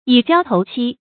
以膠投漆 注音： ㄧˇ ㄐㄧㄠ ㄊㄡˊ ㄑㄧ 讀音讀法： 意思解釋： 比喻相合而密不可分。